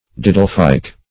didelphyc - definition of didelphyc - synonyms, pronunciation, spelling from Free Dictionary Search Result for " didelphyc" : The Collaborative International Dictionary of English v.0.48: Didelphyc \Di*del"phyc\, a. (Zool.)